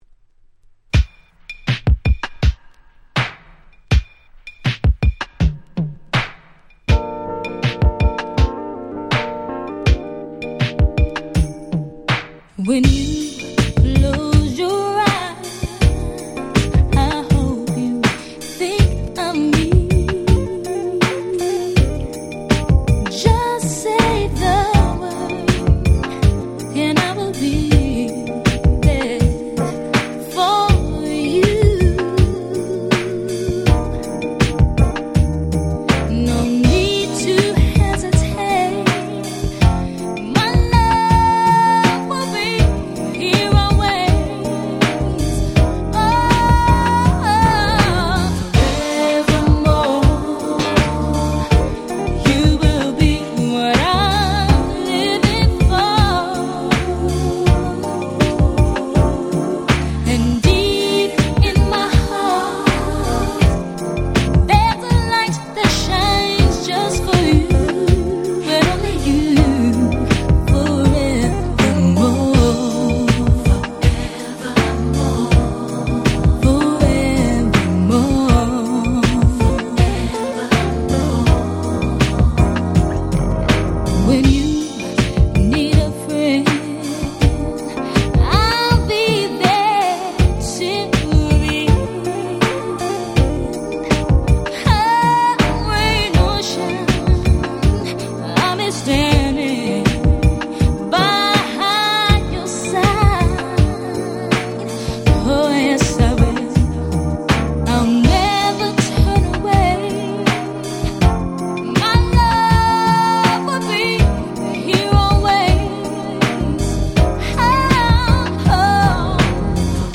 96' Smash Hit R&B !!